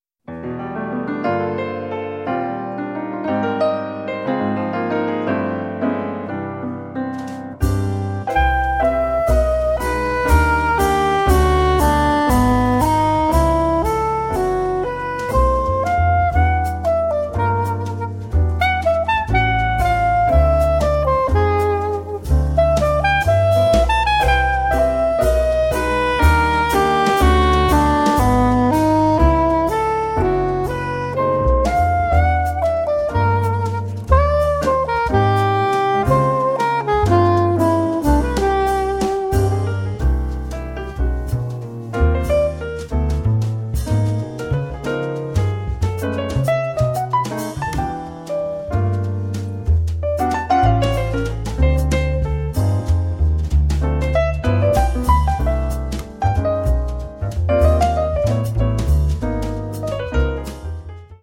piano
sax tenore, soprano
contrabbasso
batteria